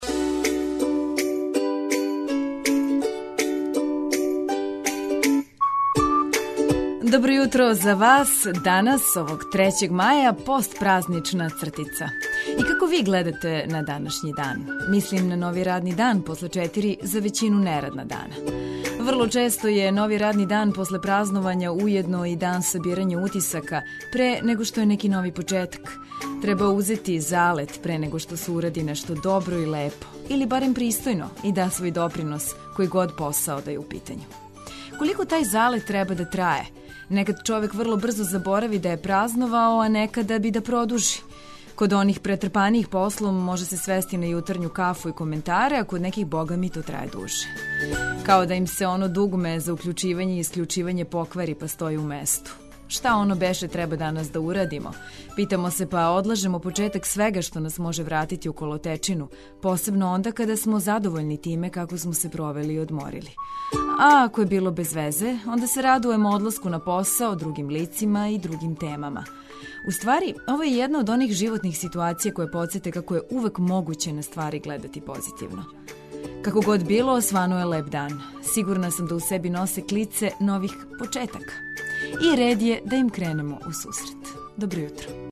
Ако је ваша одлука буђење уз Београд 202, онда ћете спремно дочекати први радни дан после првомајских празника. Мотивисани ведром музиком сазнаћете најважније информације и спремно закорачити у нове изазове.